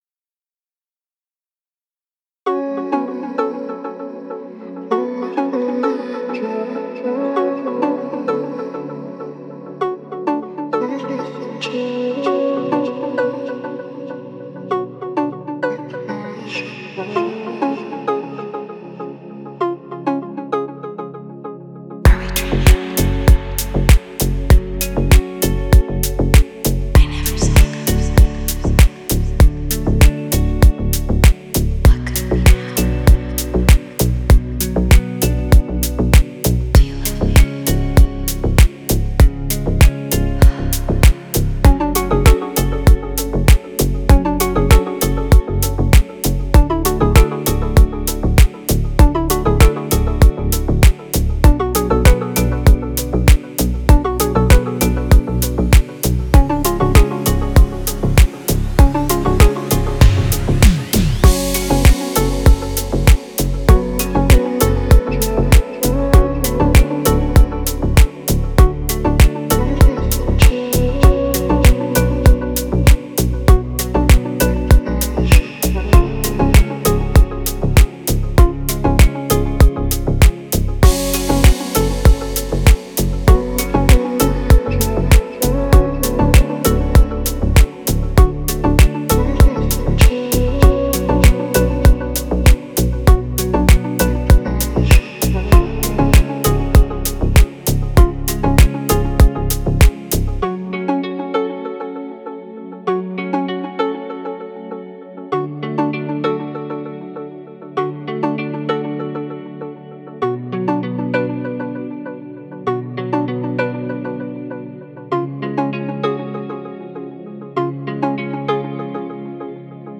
Красивые песни и треки
красивая музыка